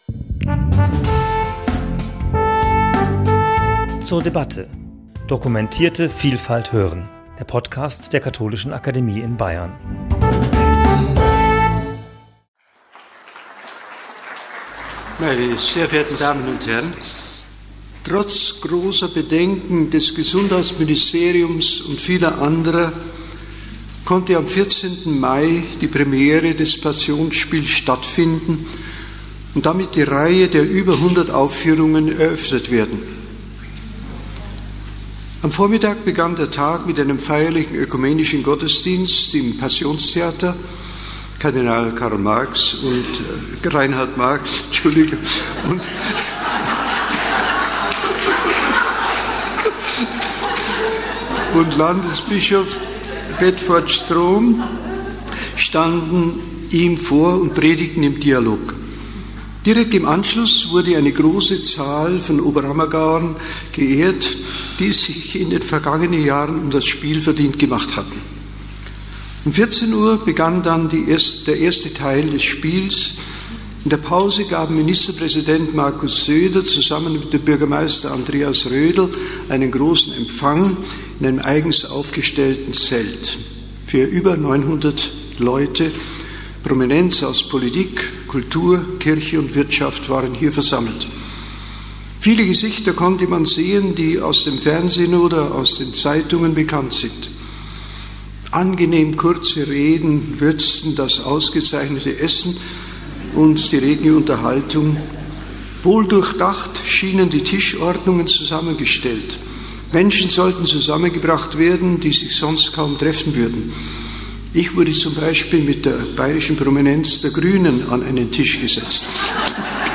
Die Katholische Akademie in Bayern nimmt die seit Mitte Mai 2022 laufenden Aufführungen der Passionsspiele in Oberammergau zum Anlass, diese wichtigen Fragen zu erörtern